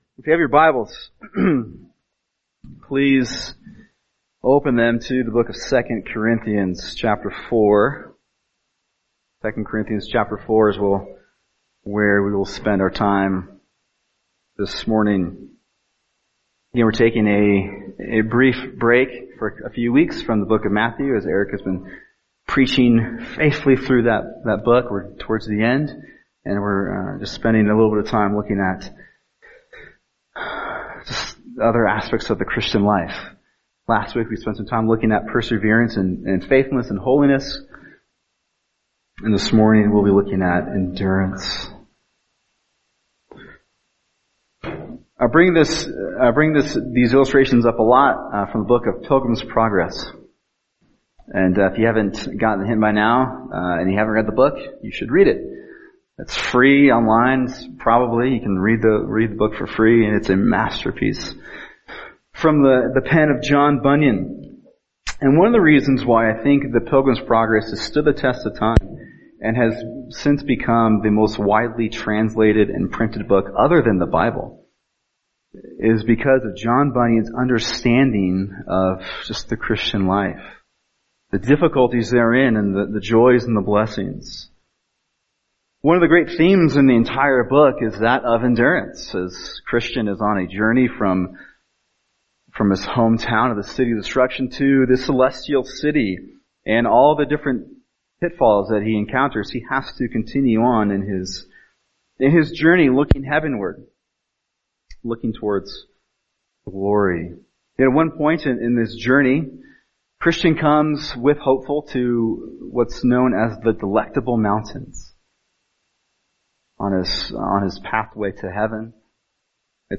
[sermon] 2 Corinthians 4:16-18 Heavenly Endurance | Cornerstone Church - Jackson Hole